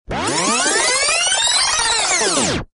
rewind.mp3